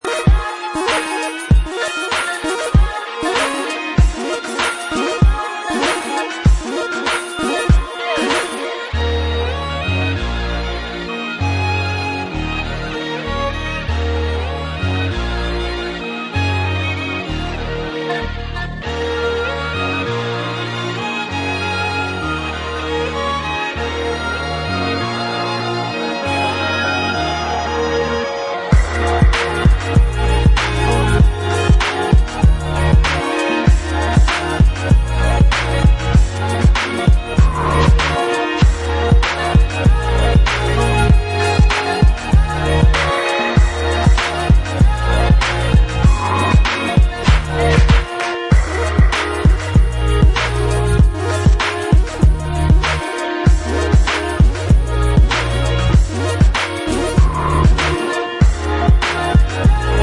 Styl: House, Lounge, Breaks/Breakbeat